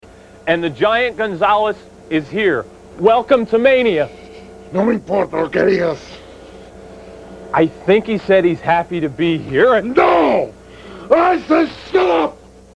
growling about in an insane manner!